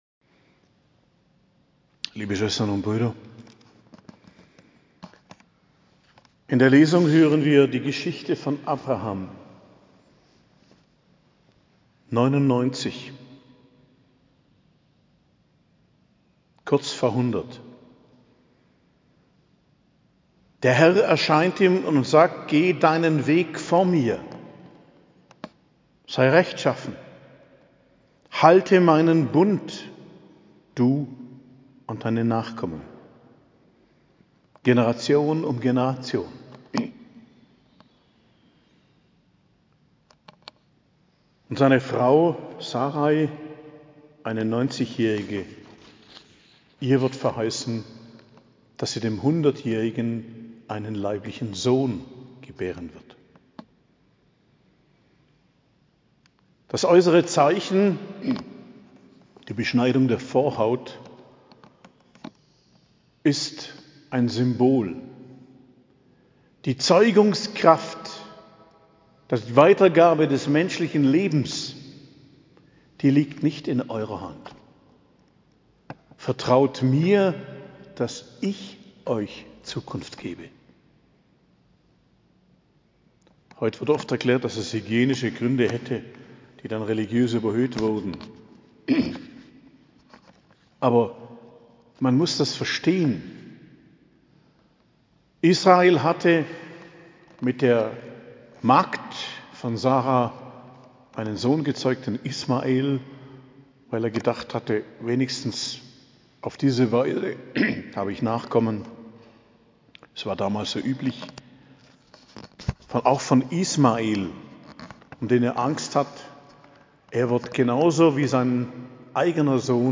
Predigt am Freitag der 12. Woche i.J., 30.06.2023